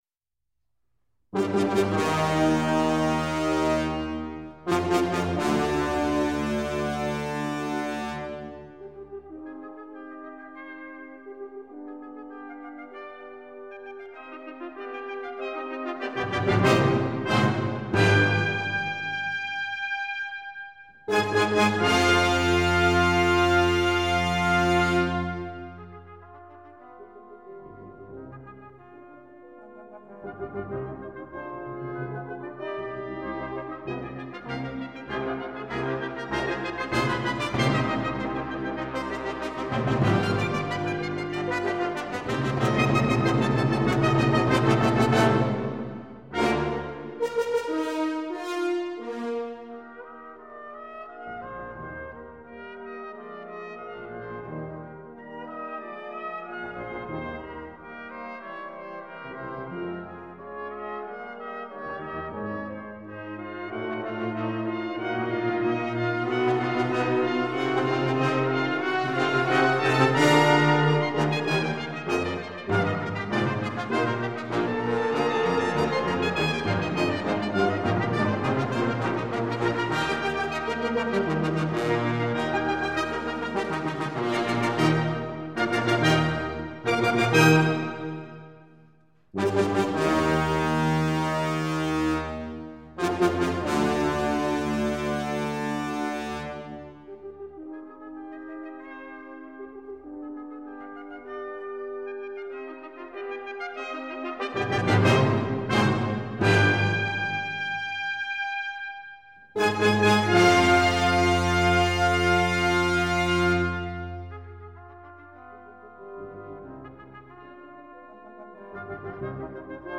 Allegro Con Brio